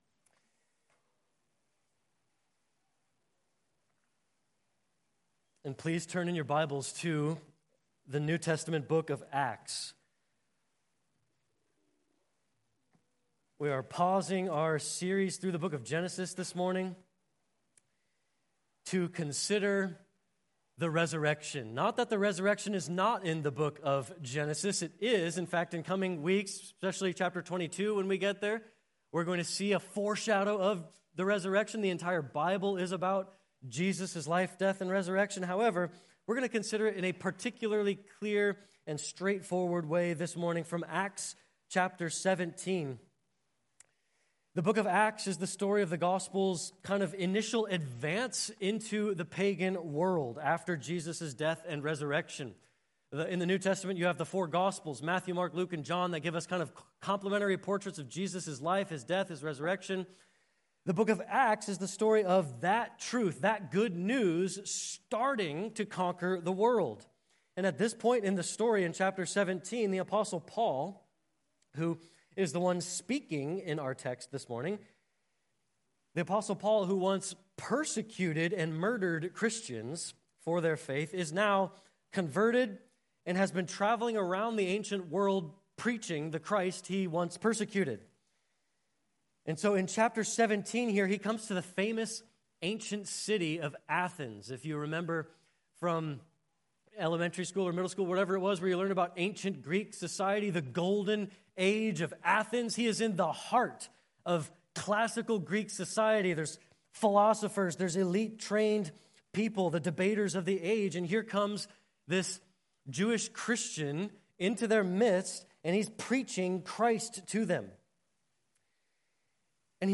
Easter Sunday Sermon 2026